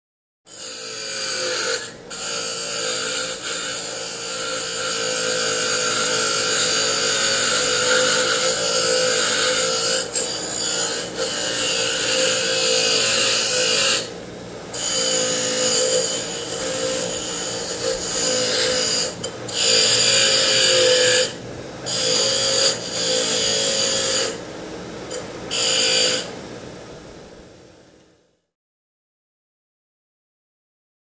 Metal Grinder | Sneak On The Lot
Metal On Grinder; Metal Grinding Against Machine Spun Stone Wheel; Motor / Fan Noise, Medium Perspective.